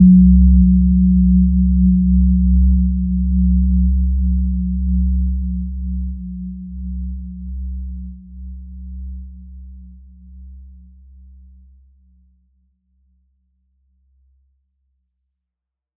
Gentle-Metallic-4-C2-mf.wav